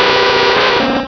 Cri de Nostenfer dans Pokémon Rubis et Saphir.